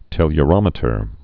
(tĕlyə-rŏmĭ-tər)